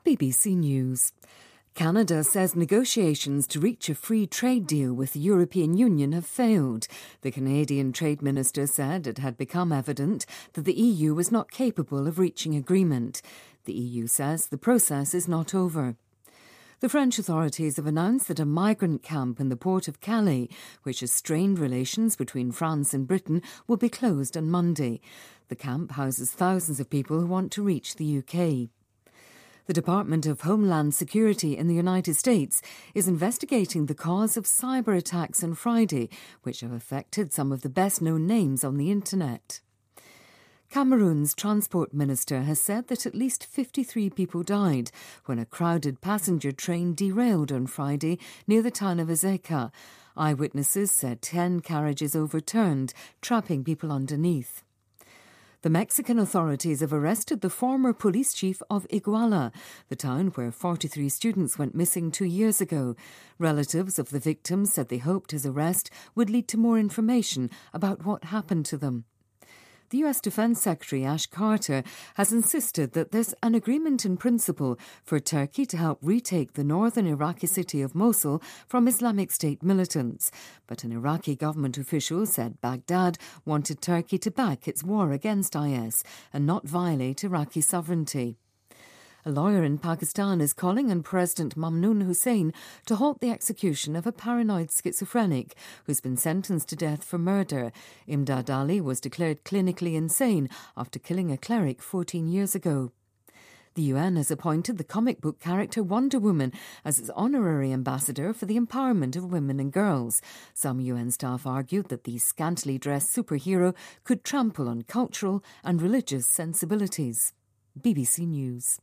BBC news,加拿大与欧盟自贸协议告吹